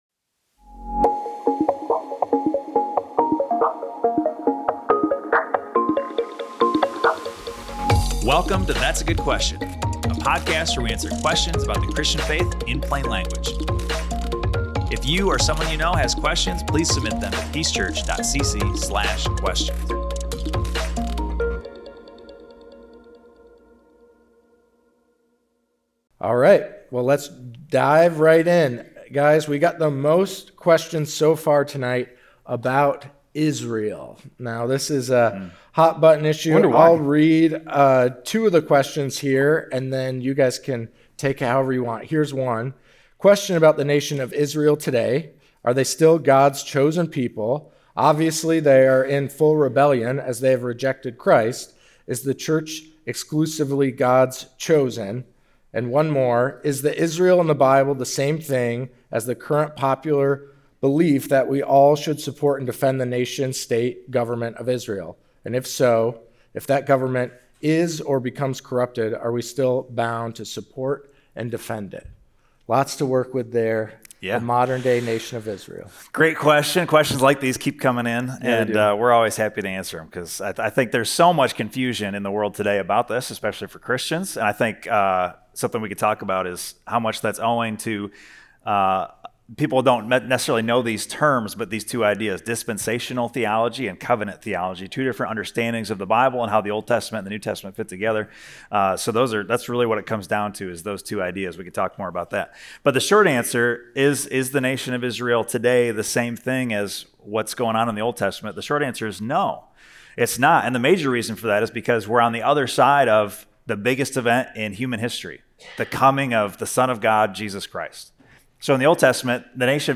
That’s a Good Question — Live Q&A, Part 1